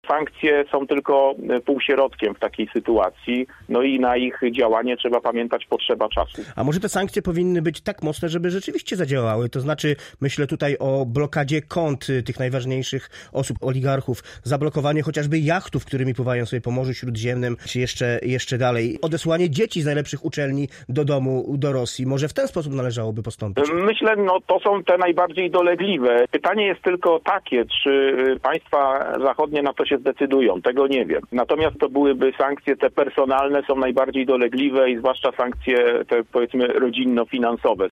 Chodzi o to, aby były jak najbardziej odczuwalne – podkreślał w porannej rozmowie na antenie Radia Zielona Góra: